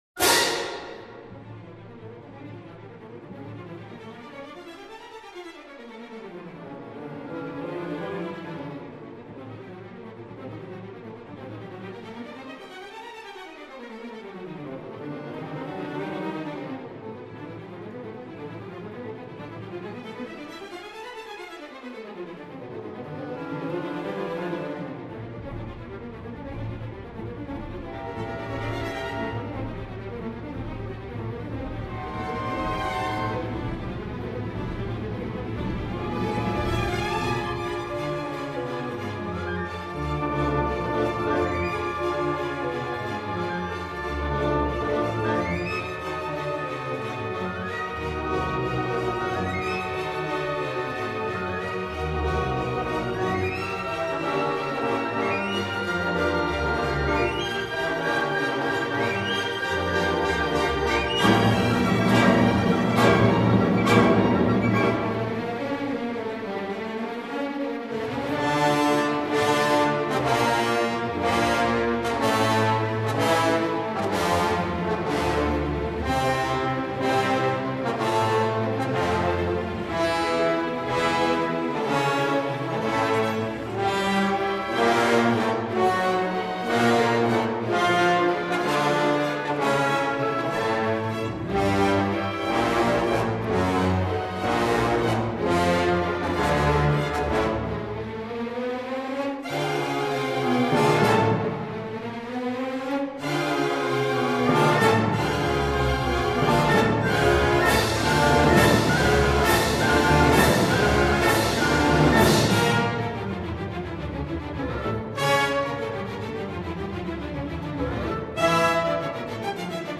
Mazeppa (poema sinfonico).mp3 — Laurea Triennale in Scienze e tecnologie della comunicazione
mazeppa-poema-sinfonico.mp3